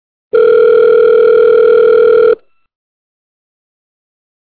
call-out.m4a